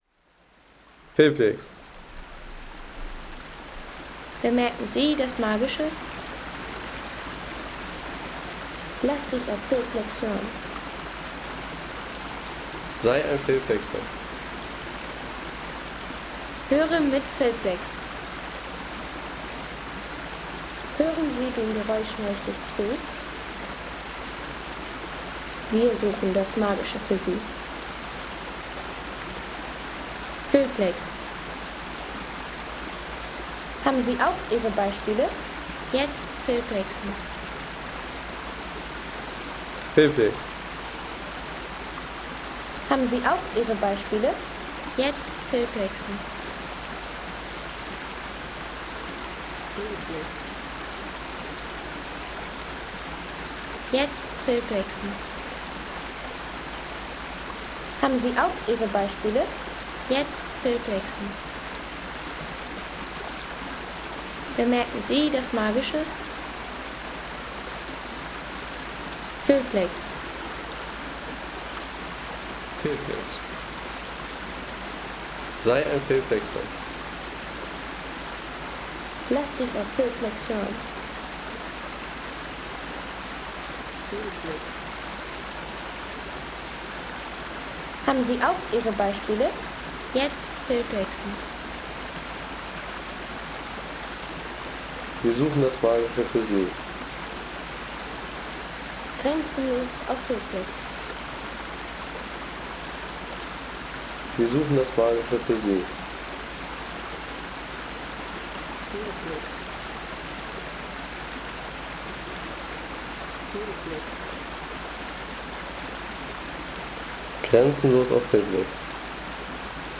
Kühgraben Stream – Water Sounds from Gesäuse Trail Crossing
Peaceful stream recording from Gesäuse National Park – gentle water flow from the Kühgraben at a mountain trail crossing.
Stream – Crossing Sound on the Rauchboden Trail
Gentle water splashing of Kühgraben Stream – recorded at a peaceful trail crossing in Gesäuse National Park, beneath the Große Buchstein peak.